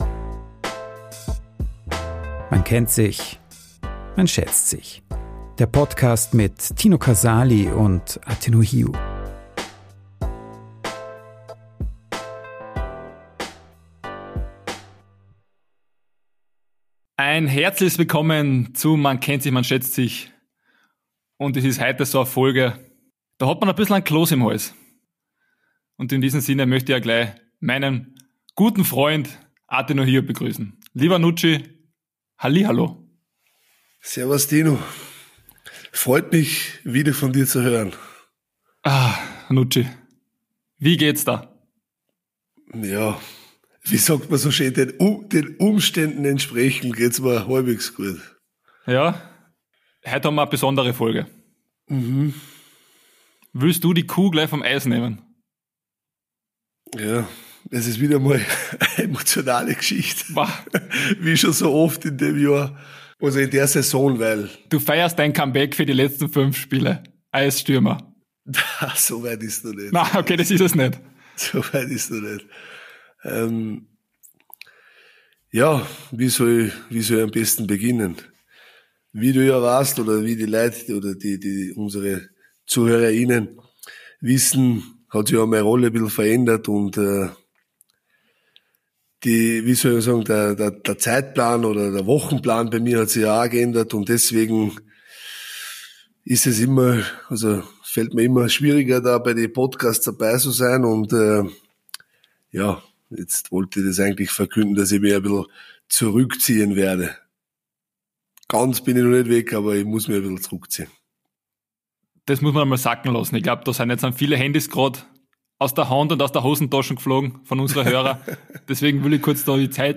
Blutende Herzen, Klöße im Hals - dennoch eine sehr gut gelaunte Folge. Und wer wird der Nachfolger von unserem Thomas Müller?